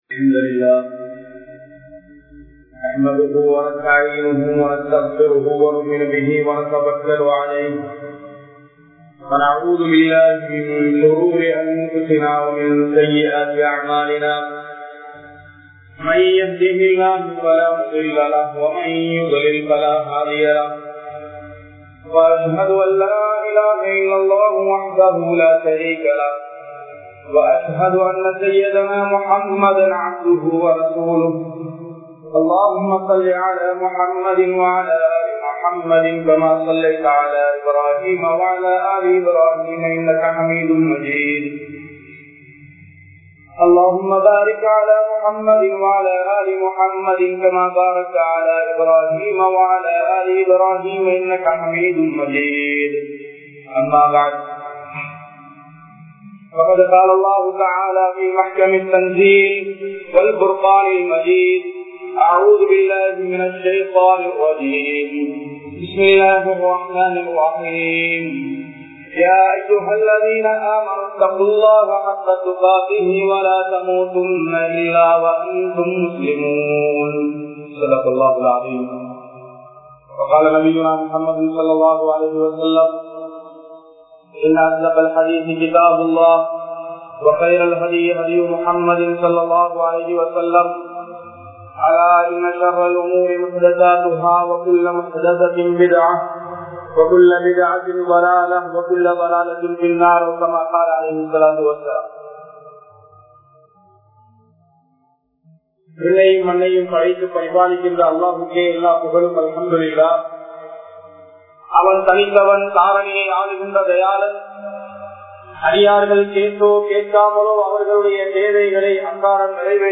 Dhulhajj Maatha Muthal 10Nattkalin Mukkiyathuvam(துல்ஹஜ் மாத முதல் 10 நாட்களின் முக்கியத்துவம்) | Audio Bayans | All Ceylon Muslim Youth Community | Addalaichenai